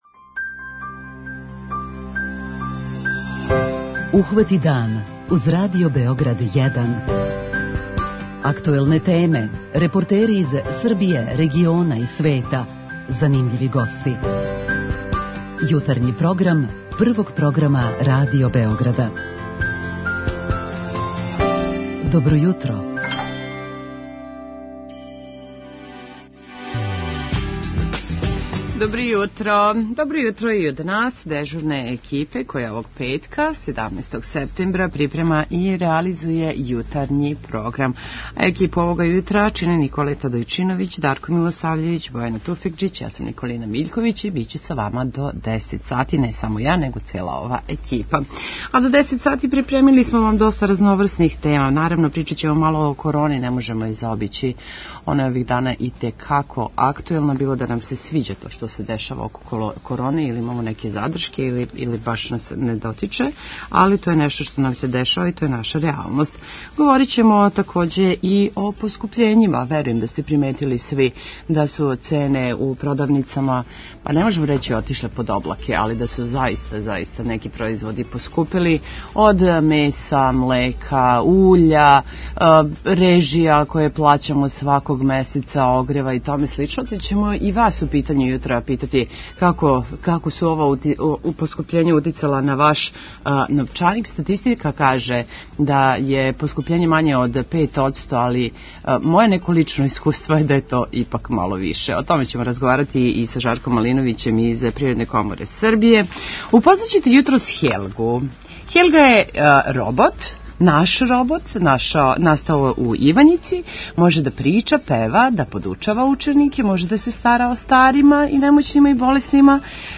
Хелга изгледа као човек, користи синтетизовани говор за комуникацију, има вештачка чула, односно сензоре, светлеће очи и огрлицу, у десној руци држи блиставу куглу, а у левој планетаријум.